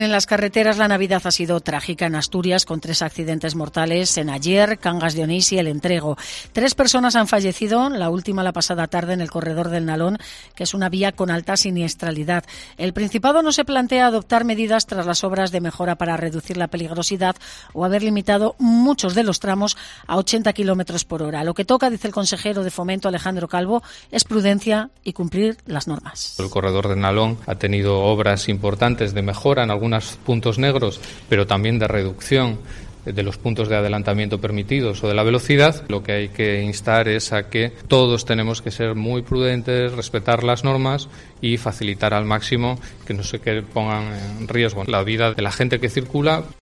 Alejandro Calvo, consejero de Fomento, sobre la siniestralidad en el Corredor del Nalón